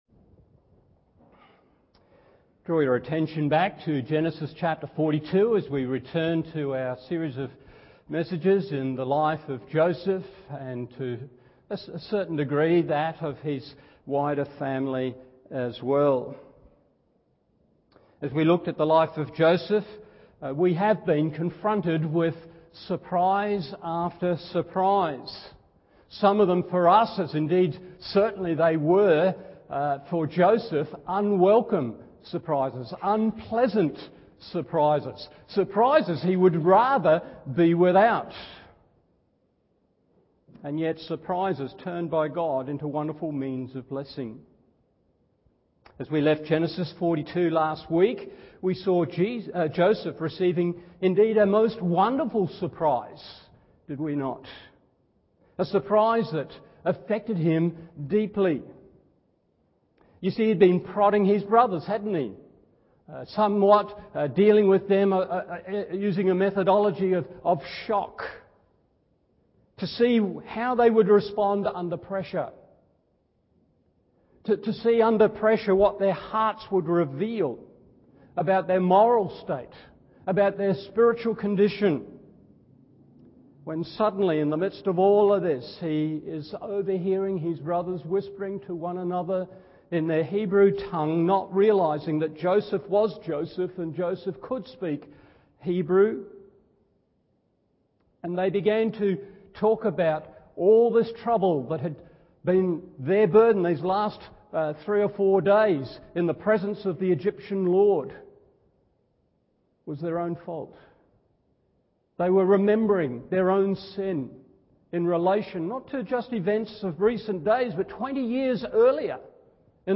Morning Service Genesis 42:25-35 1. An Expression of Kindness 2. An Exclamation of Alarm 3. An Evidence of Change…